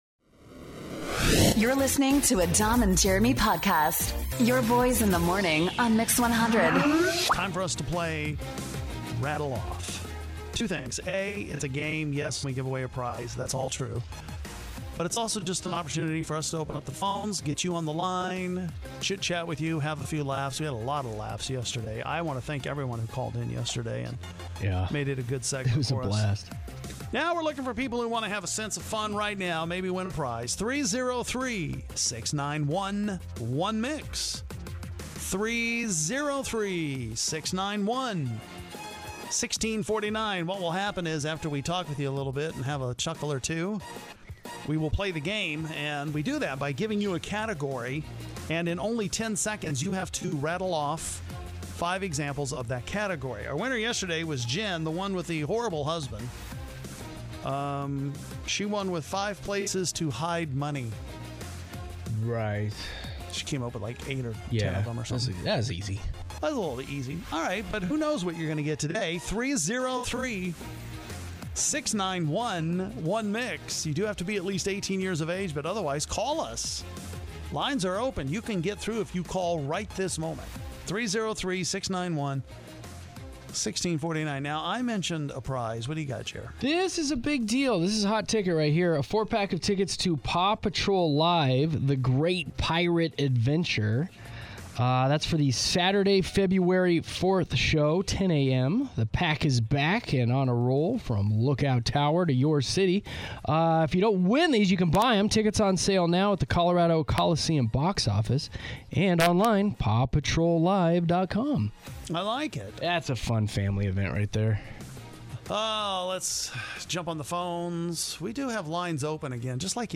We have so much fun talking to our listeners during this game of Rattle Off.